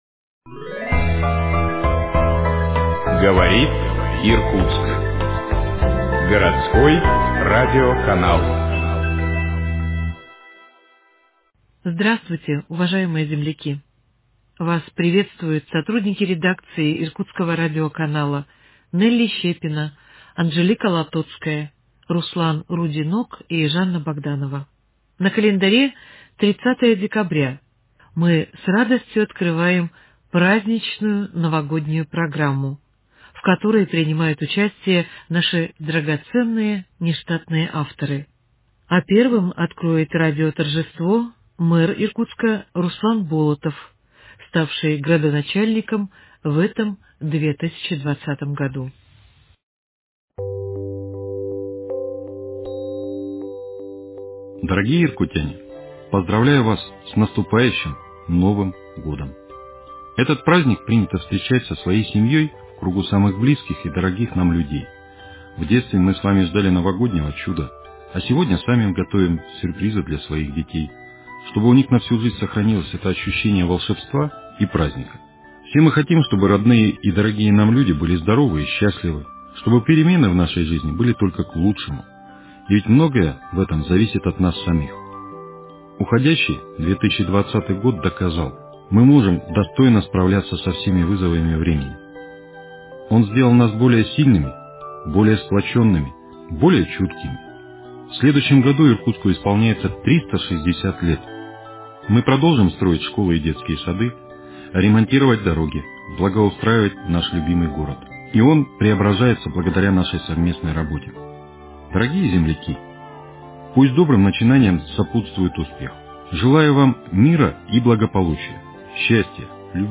Поздравления от ведущих авторских циклов.